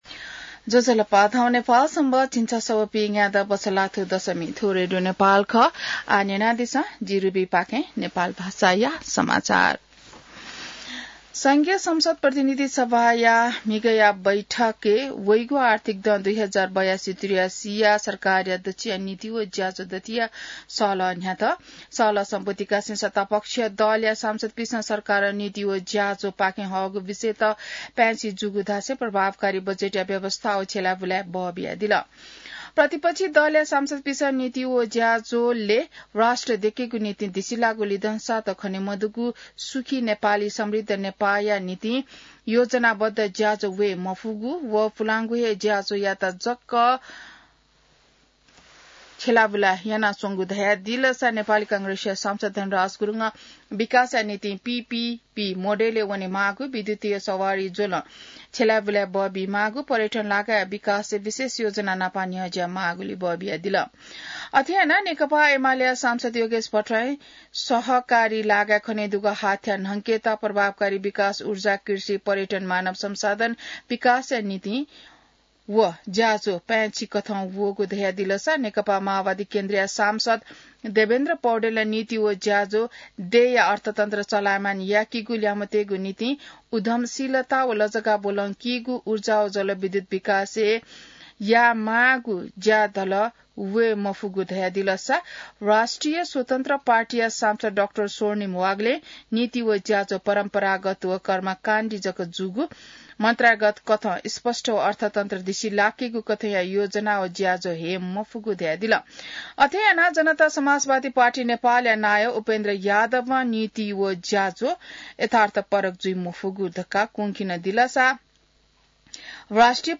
An online outlet of Nepal's national radio broadcaster
नेपाल भाषामा समाचार : २४ वैशाख , २०८२